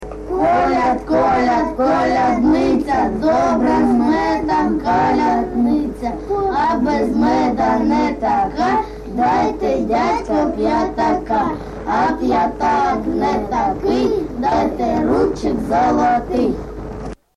ЖанрКолядки
Місце записус. Келеберда, Кременчуцький район, Полтавська обл., Україна, Полтавщина
ВиконавціГурт дітей (невід.)